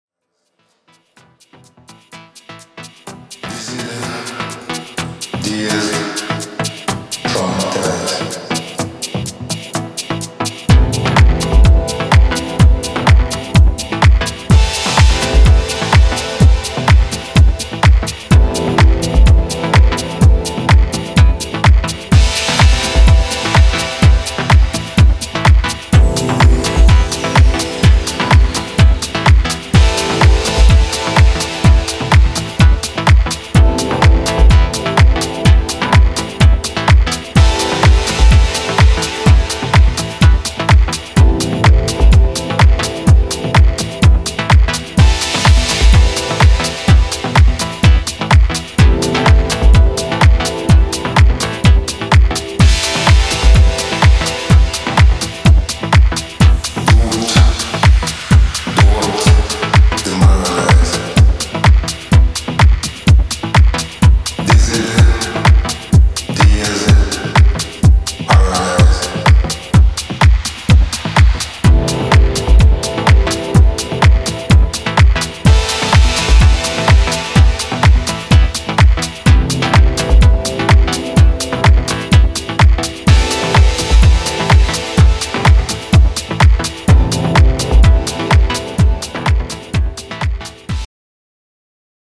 エコーをまとったラガトースティング、スタッカートしたダブリフ、硬いキックが面で押してくる